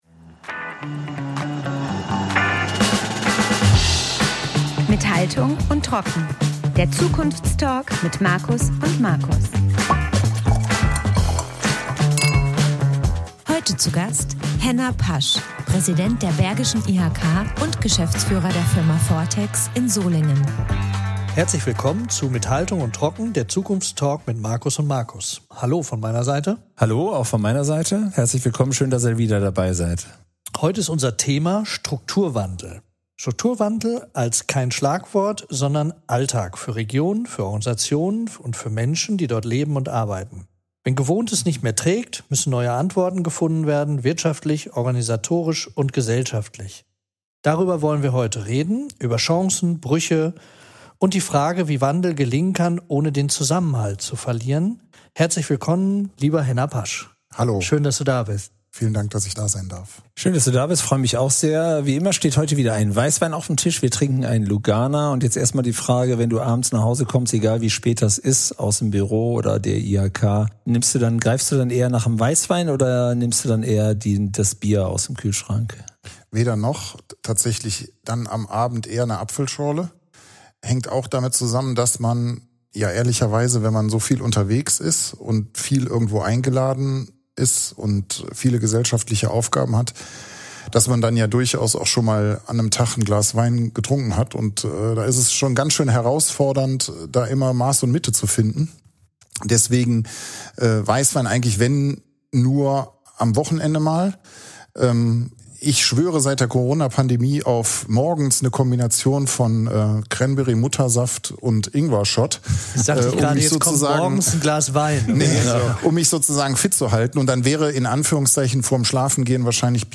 Ein Gespräch über regionale Stärke, unternehmerische Verantwortung und die Frage, wie Zukunft entsteht, wenn Menschen bereit sind, neu zu denken – und gemeinsam zu handeln.